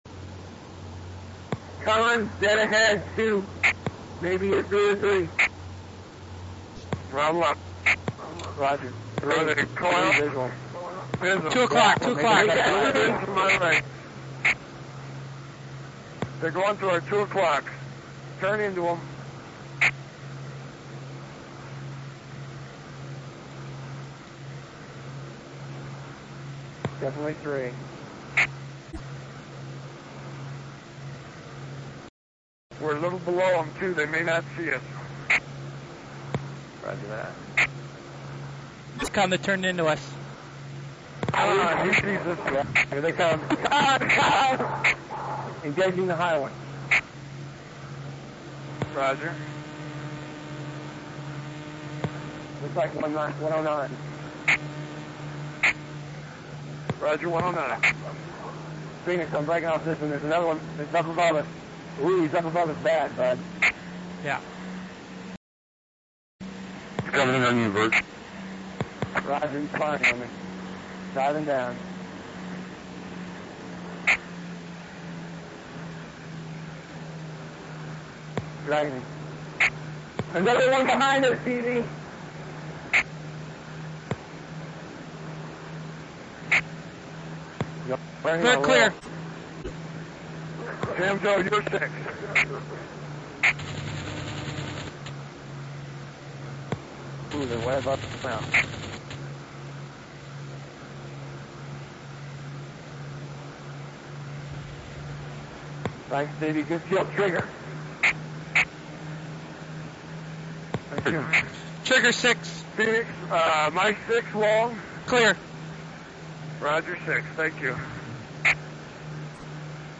Here's an MP3 I recorded of my squad, the Haze, during a heated S3 encounter. We were flying P38's against 109's over Tunisia.